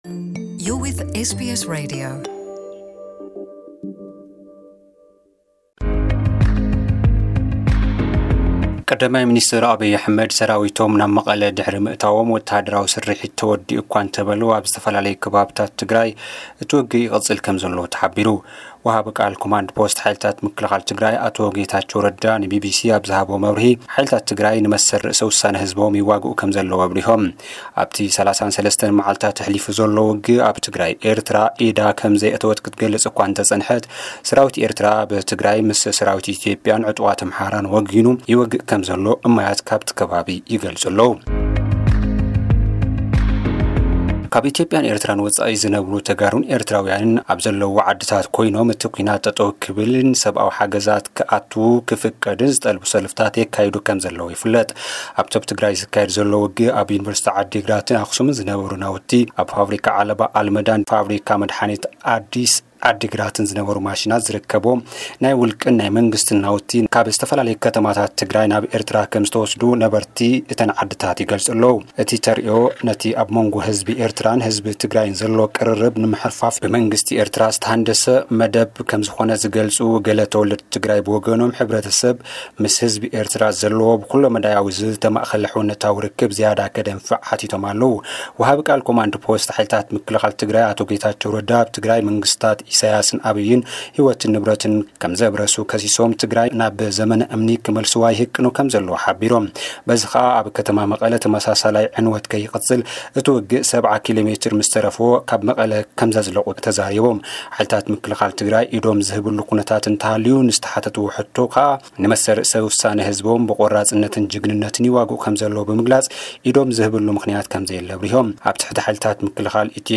ጸብጻብ ዜናታት (071220) * ቀ/ሚ ኲናት ተወዲኡ’ኳ እንተበለ ኣብ ዝተፈላለዩ ከባቢታት ትግራይ ውግእ ይቅጽል አሎ፡ * ኣብ ደገ ዝነብሩ ኤርትራውያንን ኢትዮጵያውያንን ኲናት ደው ክብልን ሰብአዊ ሓገዝ ክአቱን ዝጽውዕ ሰላማዊ ሰልፊ አካይዶም፡ *